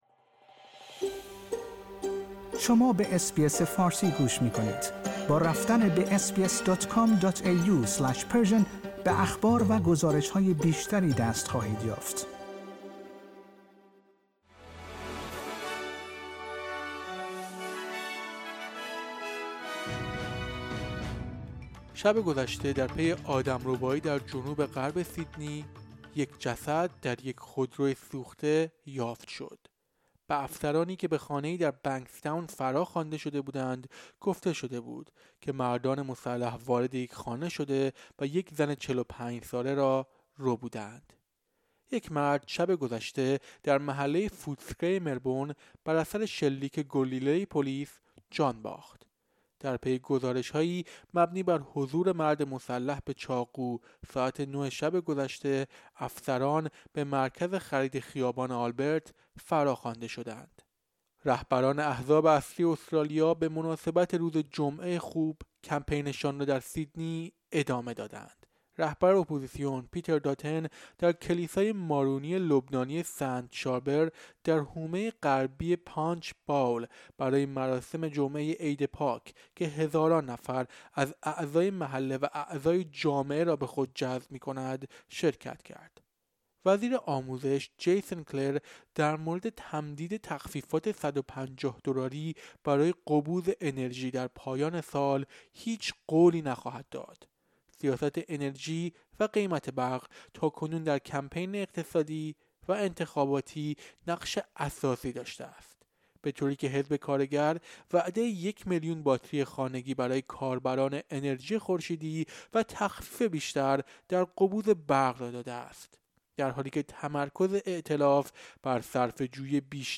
در این پادکست خبری مهمترین اخبار امروز جمعه ۱۸ آپریل ارائه شده است.